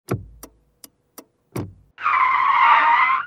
CosmicRageSounds / wav / general / highway / oldcar / switch.wav
switch.wav